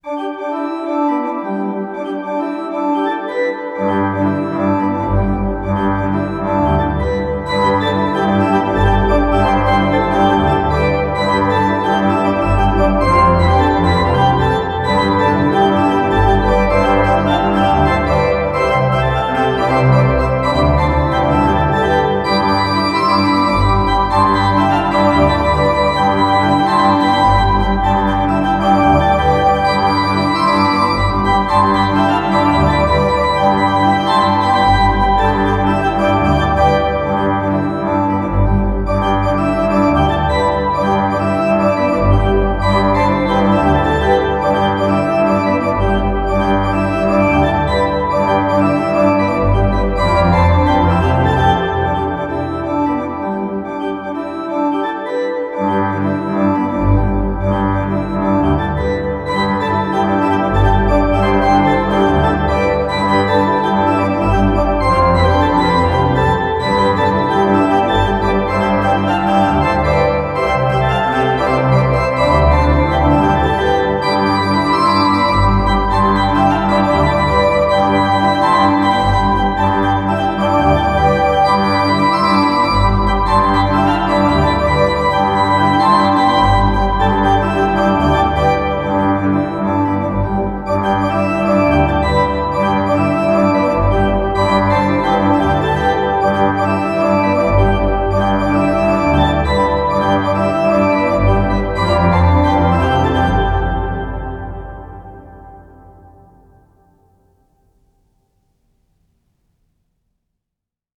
pipe_organ